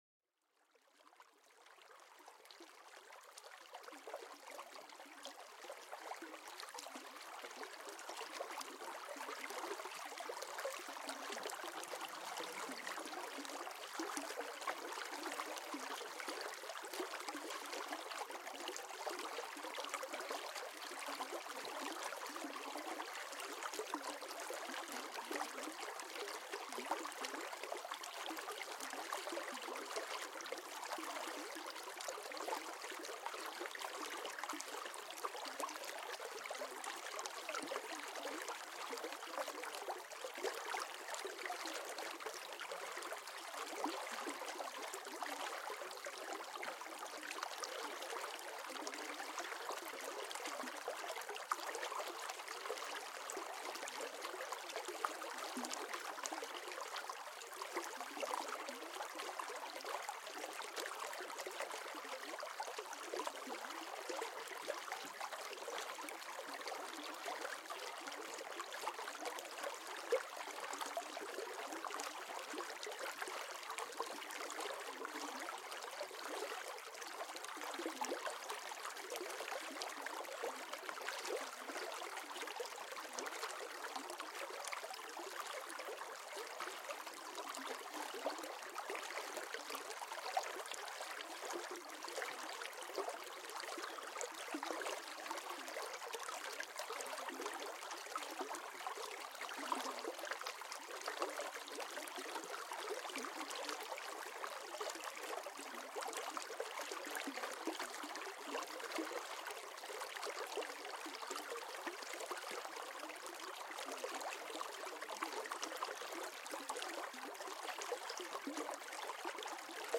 Sumérgete en la armonía natural de un río tranquilo, donde el suave susurro del agua despierta una tranquilidad profunda.